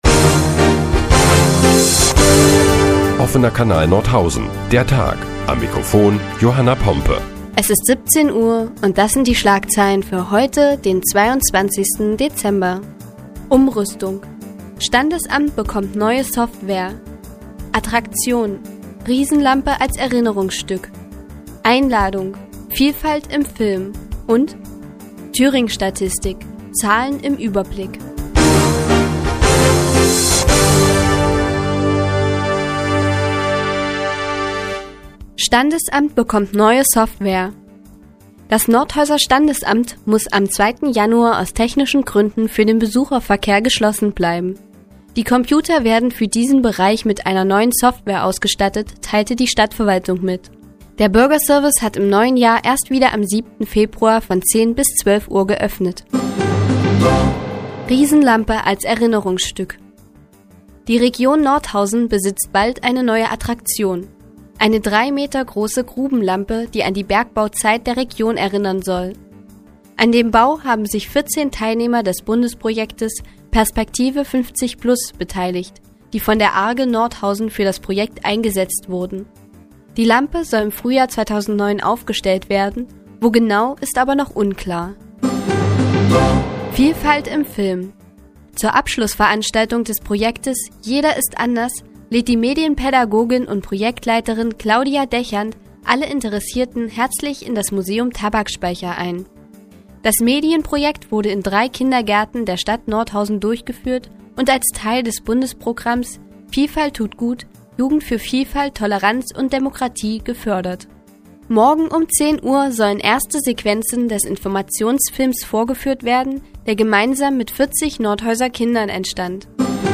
Die tägliche Nachrichtensendung des OKN ist nun auch in der nnz zu hören. Heute geht es unter anderem um eine Riesenlampe für Nordhausen und um Vielfalt im Film.